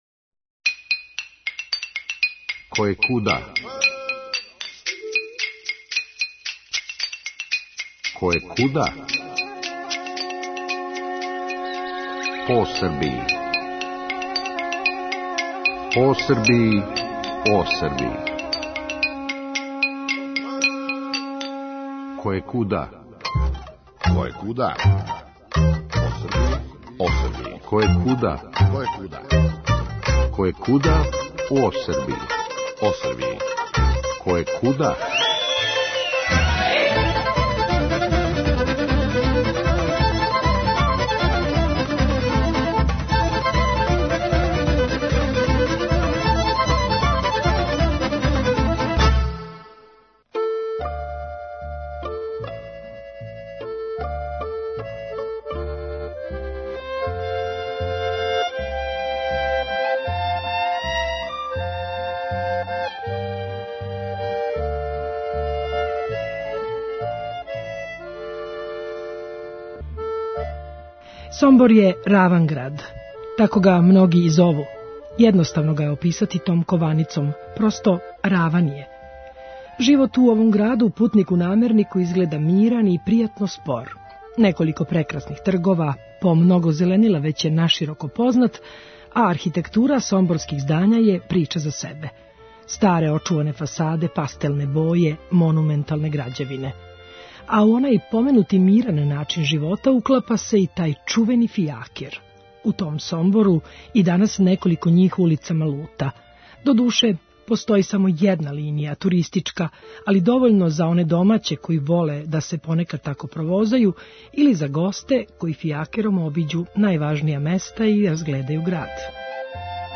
Емисија Радио Београда 1 која ће вас, баш како јој и назив говори, водити од Келебије до Медвеђе, од Бајине Баште до Неготина - у сеоца и градове, у прошлост и будућност, у стварно и могуће.